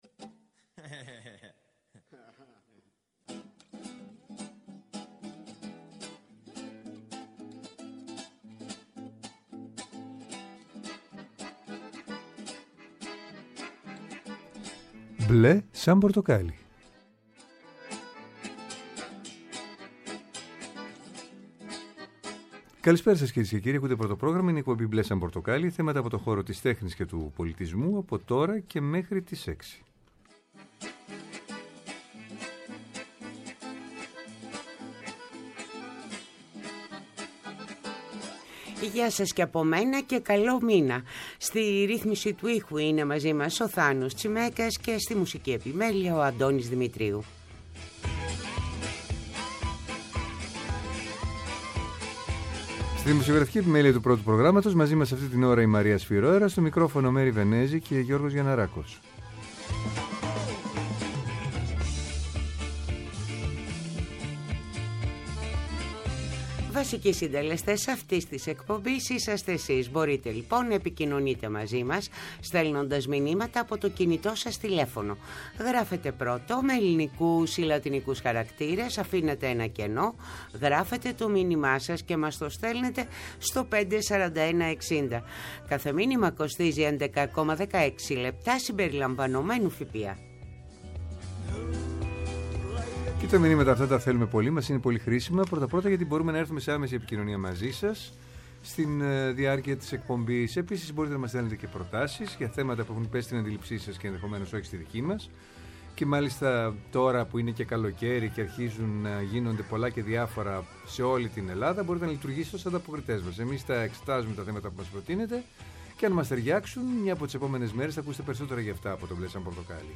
Τηλεφωνικά καλεσμένοι μας είναι οι: